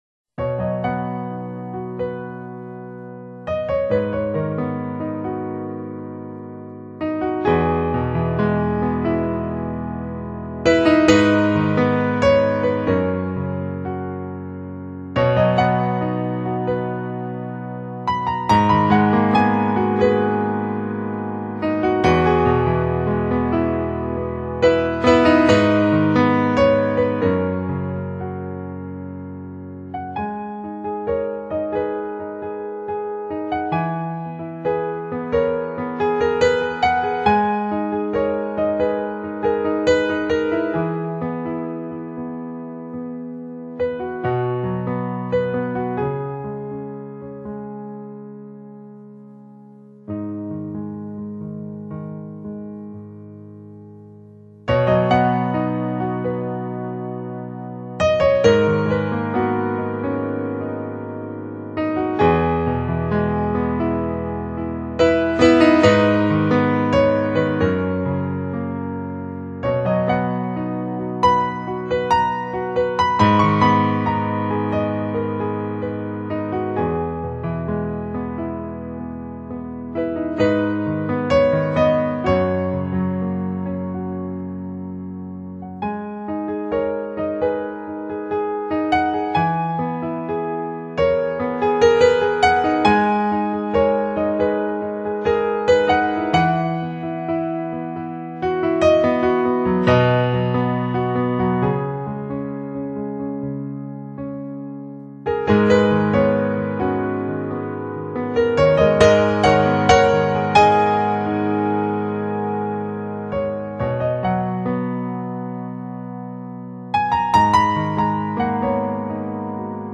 主题鲜明的钢琴演奏专辑，琢磨出钢琴静谧、激情的双面美感
最后乐曲结束在虫鸣与风铃声中，让人不觉也感受到一份晚风的清凉。